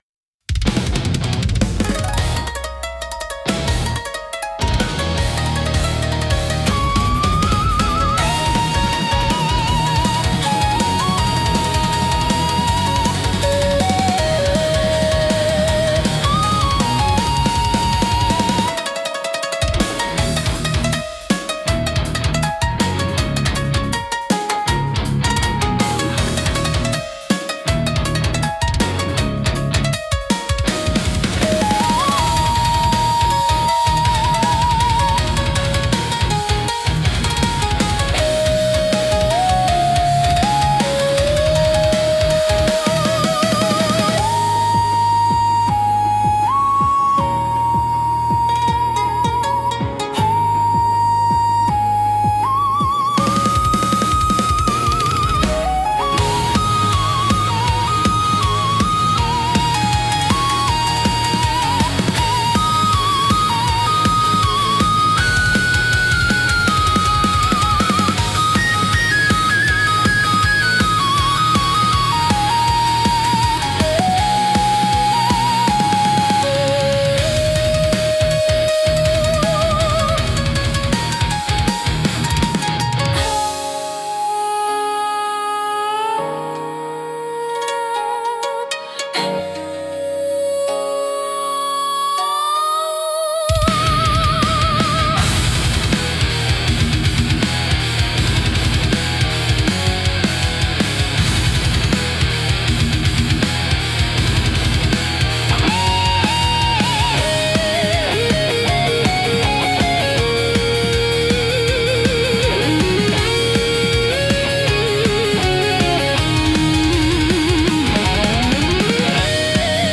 尺八の幽玄な響きと琴の繊細な調べが、重厚なギタートーンや高速ビートと絡み合い、独自の緊張感とダイナミズムを生み出します。
聴く人に力強さと神秘性を同時に感じさせ、日本古来の精神と現代のエネルギーを融合したインパクトを与えます。